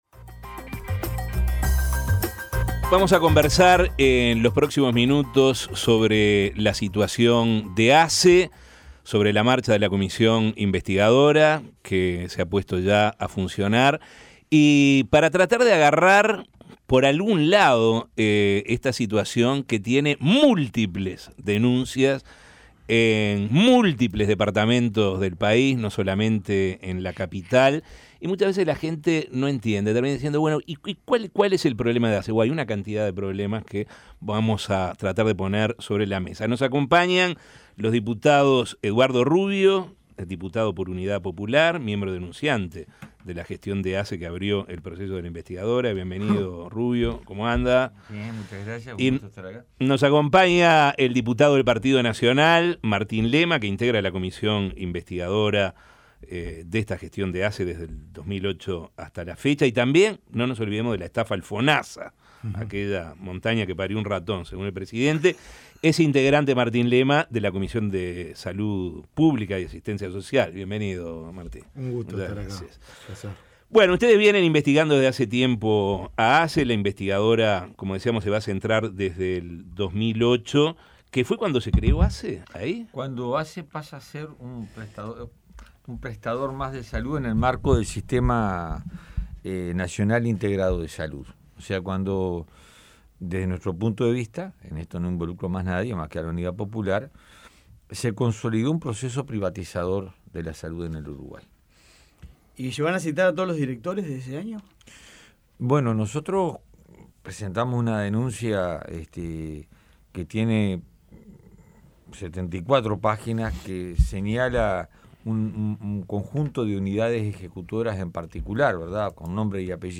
Escuche la entrevista de La Mañana: